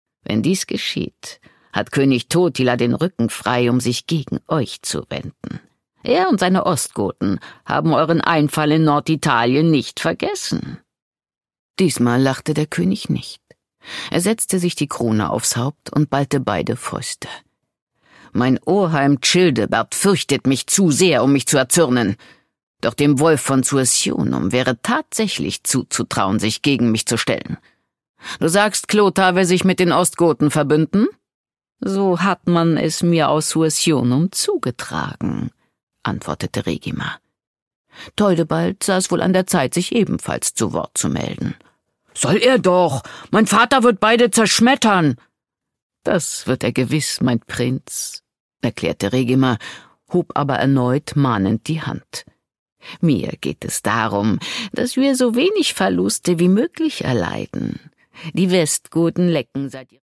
Produkttyp: Hörbuch-Download
gewohnt mitreißend und mit warmer, melodiöse Stimme.